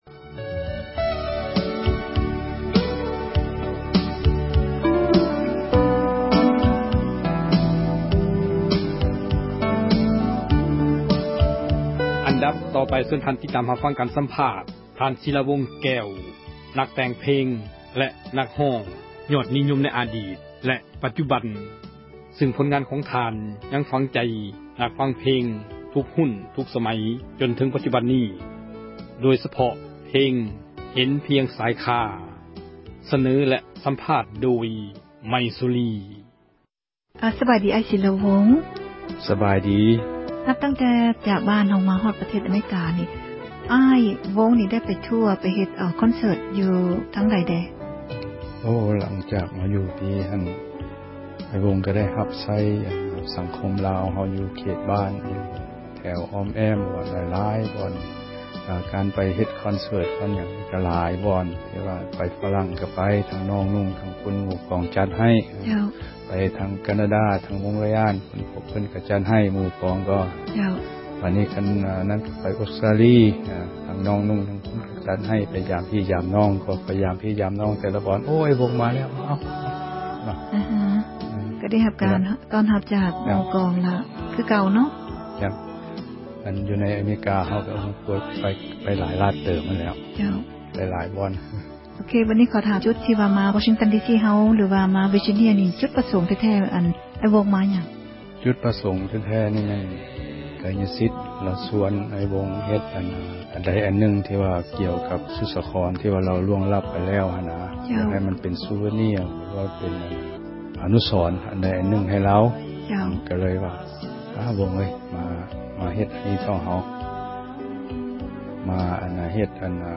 ການສຳພາດ ທ່ານ ສີລາວົງ ແກ້ວ ນັກແຕ່ງເພງ ນັກຮ້ອງ ຍອດນິຍົມ ໃນອະດິດ ແລະ ປັດຈຸບັນ ຊຶ່ງຜົນງານ ເພງຂອງທ່ານ ຍັງຝັງໃຈ ນັກຟັງເພງ ທຸກຮຸ້ນ ທຸກສມັຍ ຈົນເຖິງ ທຸກວັນນີ້ ໂດຍສະເພາະ ເພງ ເຫັນພຽງຊາຍຄາ.